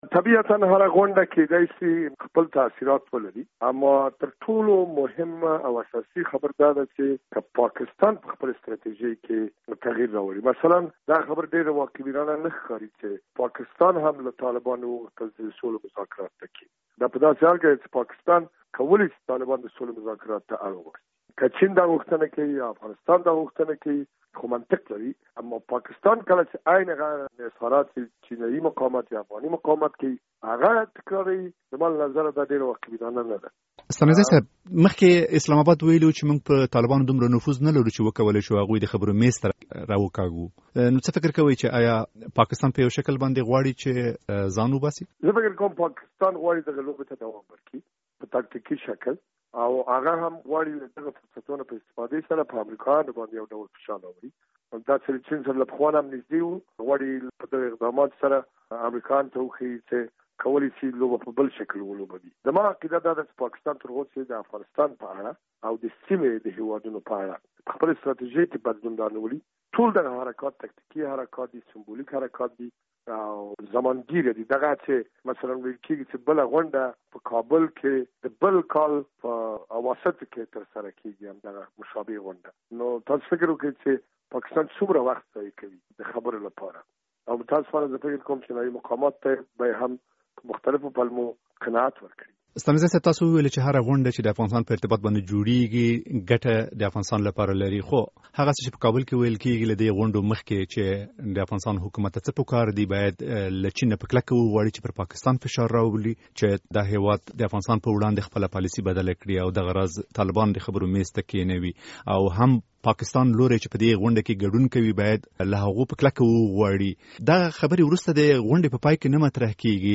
له ښاغلي ستانکزي سره مرکه